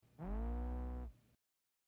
The Black Hole FX - Maximillian's head turns
The_Black_Hole_FX_-_Maximillian_s_head_turns.mp3